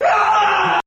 Screaming Male Scream 3